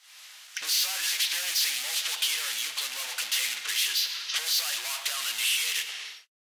RadioAlarm2.ogg